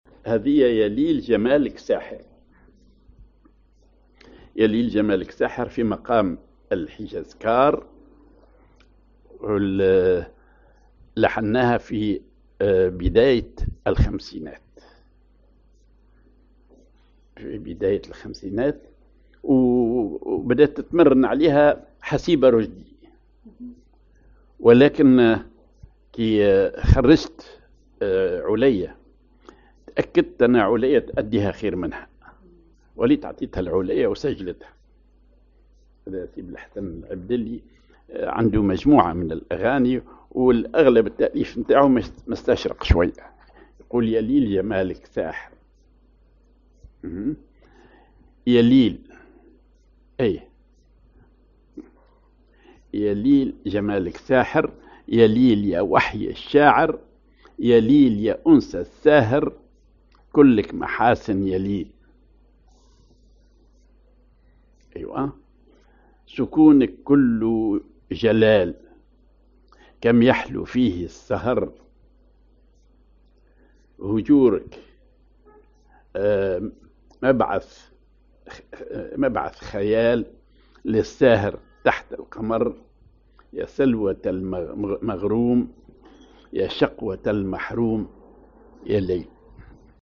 Maqam ar الحجاز كار
genre أغنية